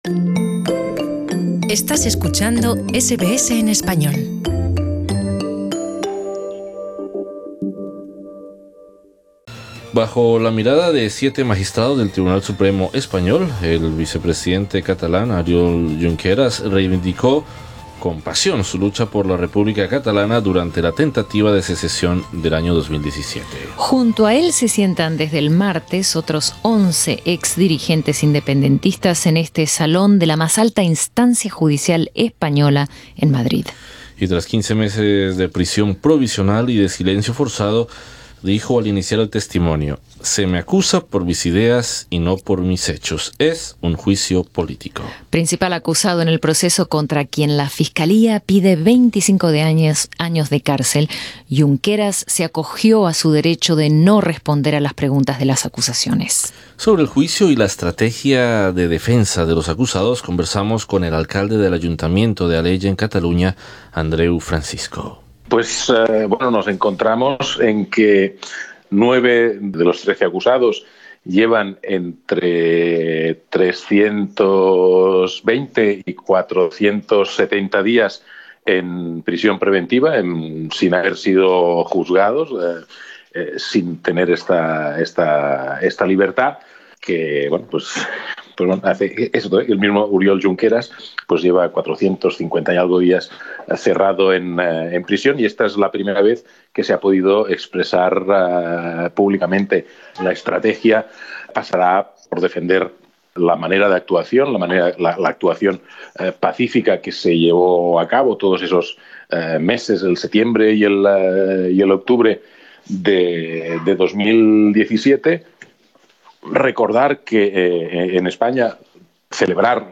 El alcalde del ayuntamiento de Alella en Cataluña y miembro del partido Esquerra Republicana de Catalunya, Adreu Francisco i Roger, habla en exclusiva para SBS Spanish sobre el juicio a los separatistas catalanes y la estrategia de defensa de los acusados.